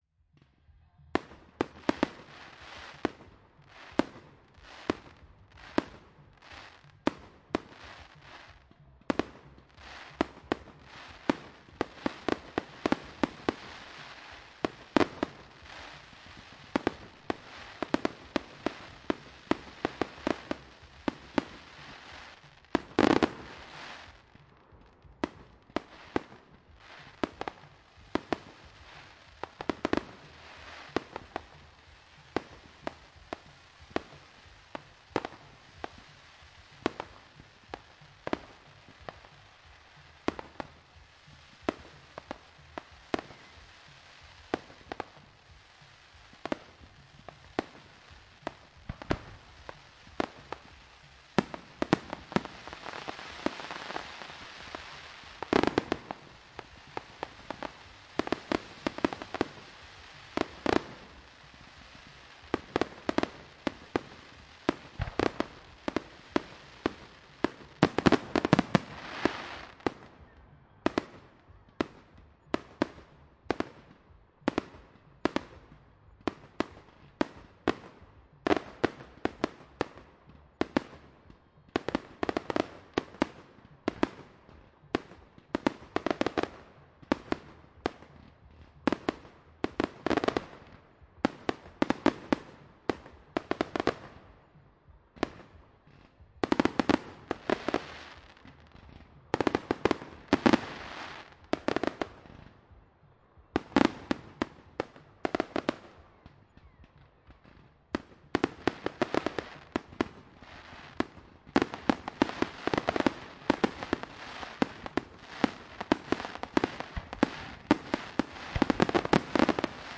描述：烟花的未加工的音频显示在Godalming，英国。我用Zoom H1和Zoom H4n Pro同时录制了这个事件来比较质量。令人讨厌的是，组织者还在活动期间抨击了音乐，因此安静的时刻被遥远的，虽然模糊不清的音乐所污染。
声音是在2017年11月3日使用“H1 Zoom录音机”录制的。